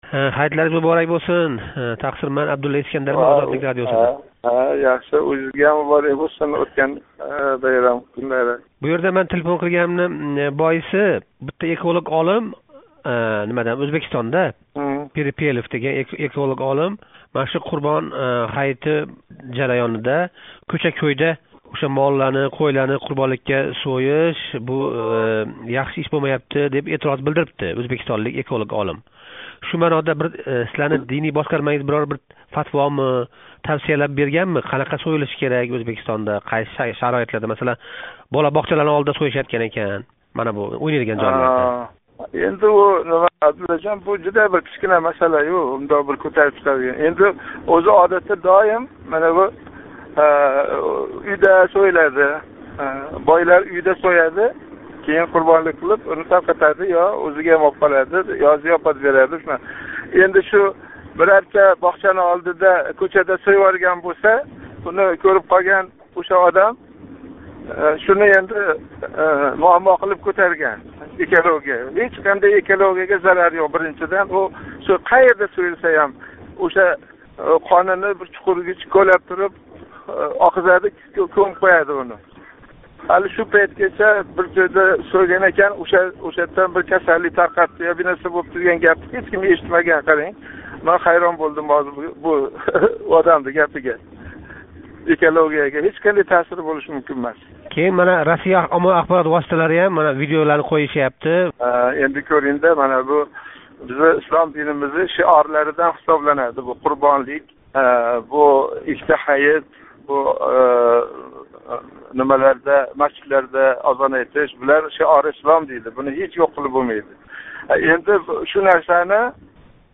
Абдулазиз Мансур билан суҳбат